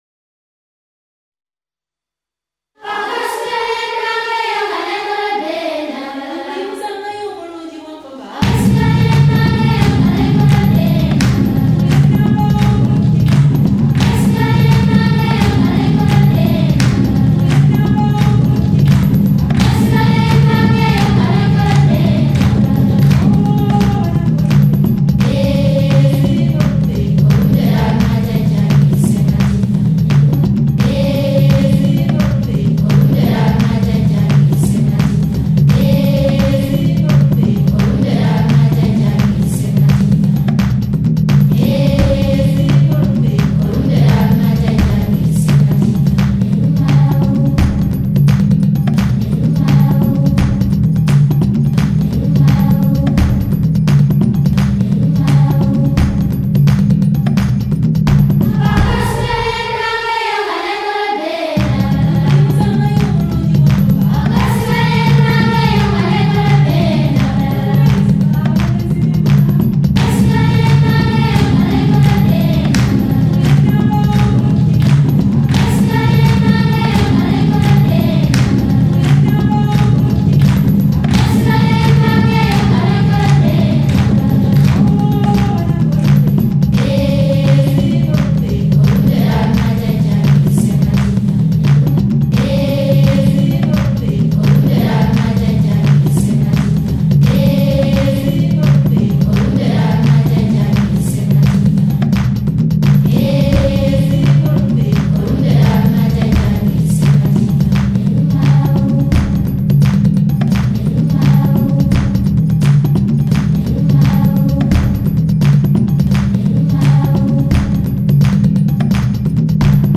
Andante [80-90] joie - voix - afrique - fete - nature